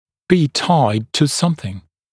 [biː taɪd tə ‘sʌmθɪŋ][би: тайд ту ‘самсин]быть привязанным к чему-либо (в т.ч. фигурально, например, к определенному значению или показателю)